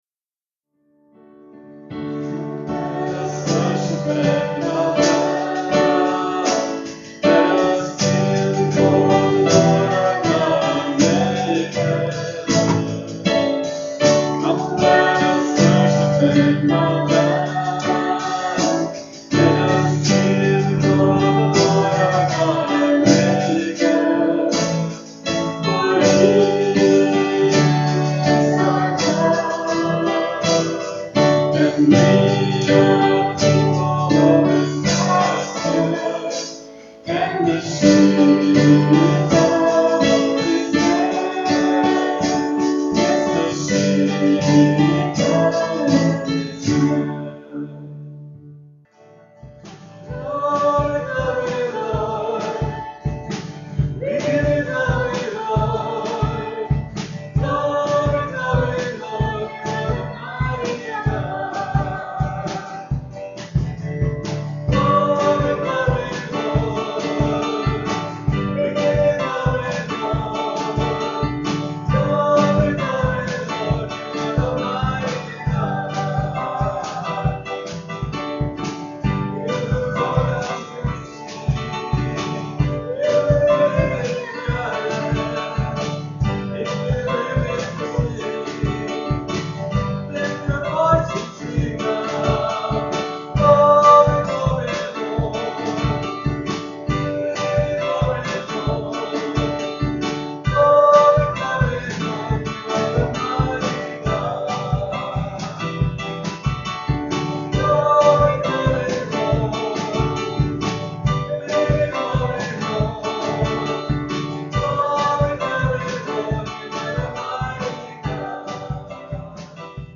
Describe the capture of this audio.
PLAY The Way of the Cross, Part 3, March 4, 2012 This recording contains a Lord's Supper observance at the end of the sound file. Scripture: Luke 10:25-37.